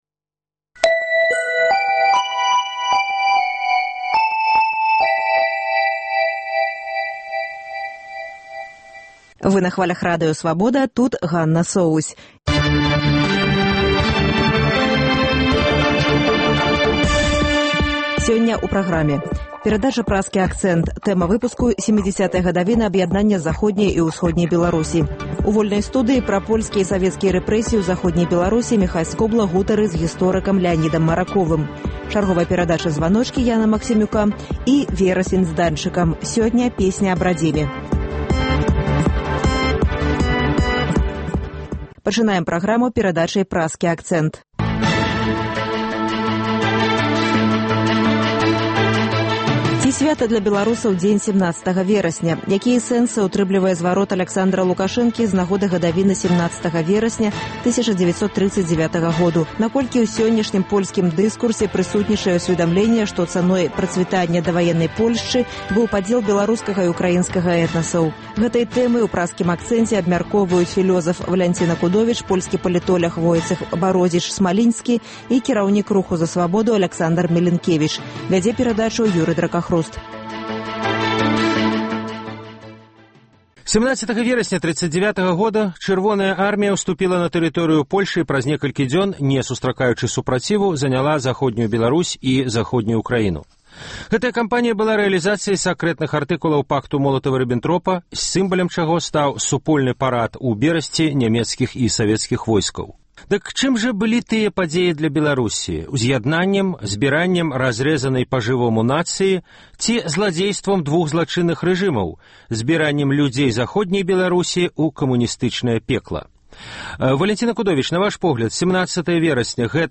Гэтыя тэмы ў “Праскім акцэнце” абмяркоўваюць